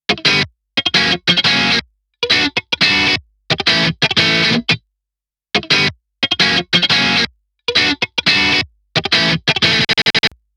ローミッドはタイトだけどドライブしていますよね。
エレキギターサウンド試聴
Neve 1073LB使用後